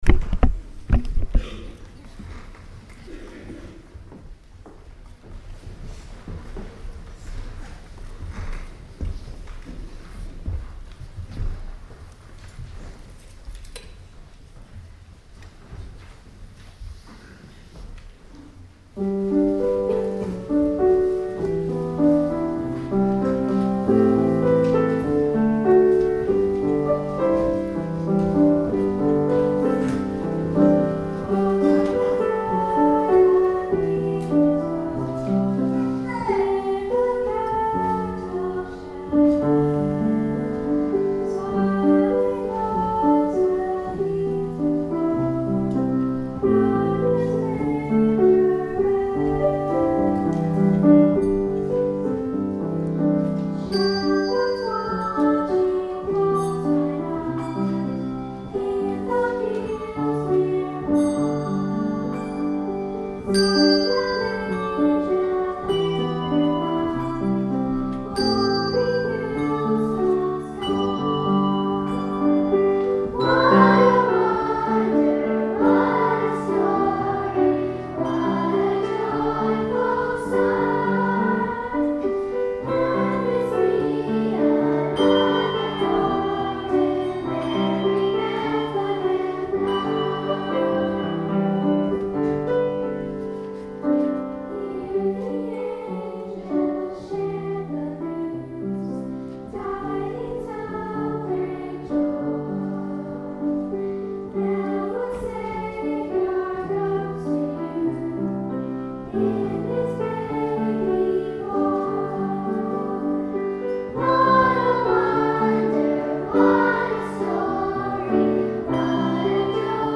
Festival of Lessons and Carols 2014